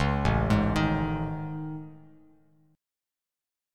AM#11 chord